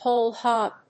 アクセントwhóle hóg 《俗語》